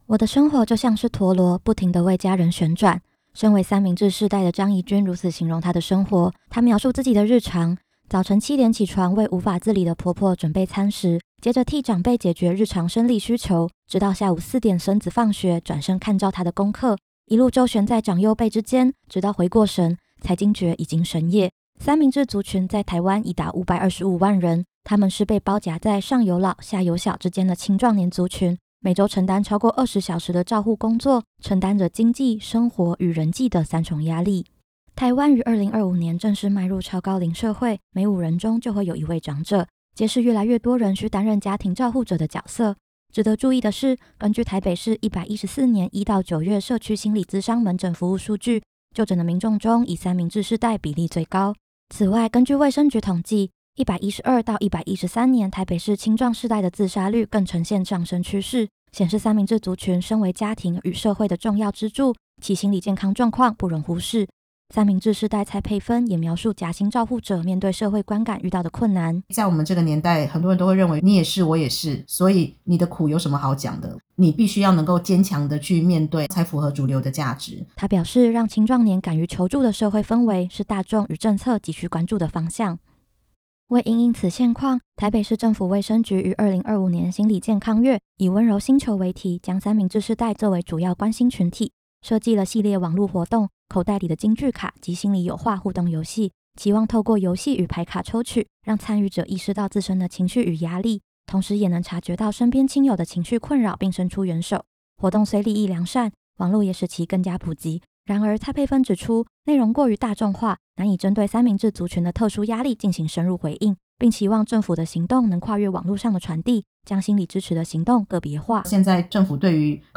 政大之聲實習廣播電台-新聞專題